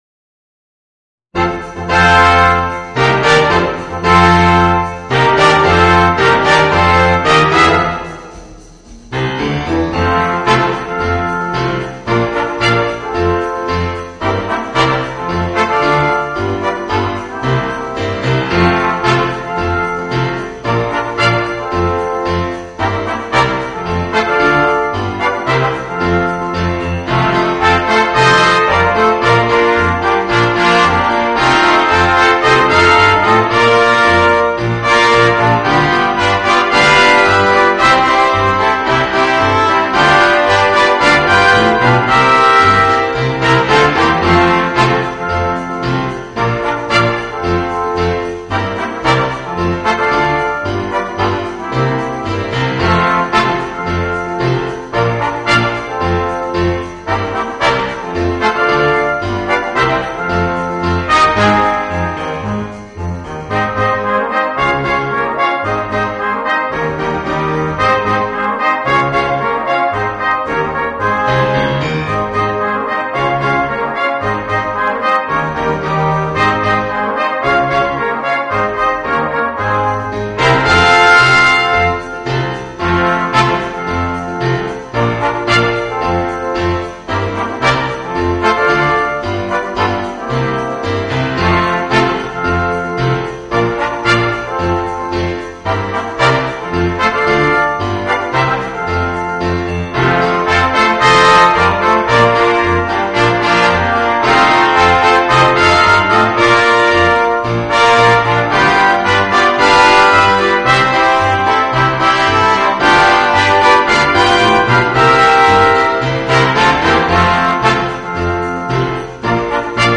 Voicing: 3 Trumpets, Trombone and Tuba